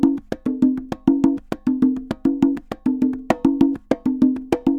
Congas_Samba 100_4.wav